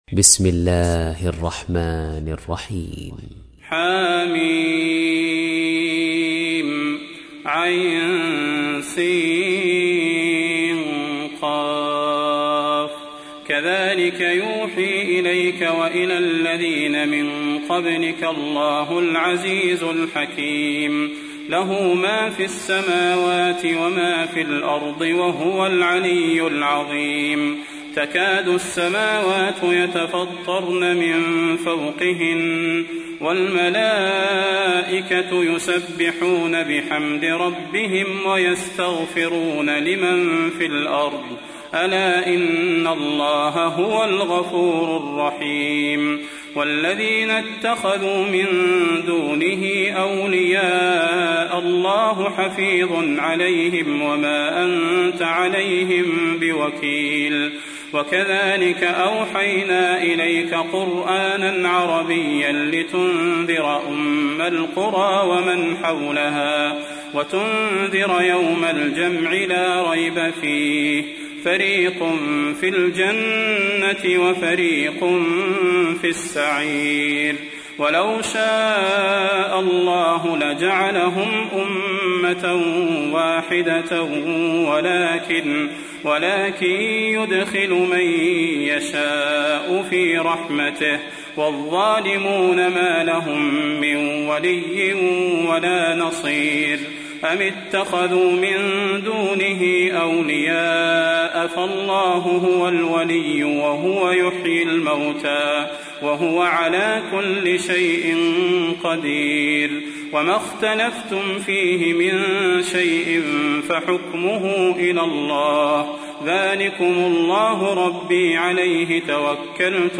تحميل : 42. سورة الشورى / القارئ صلاح البدير / القرآن الكريم / موقع يا حسين